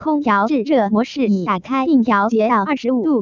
esp-tts / samples / S3_xiaole_speed4.wav
S3_xiaole_speed4.wav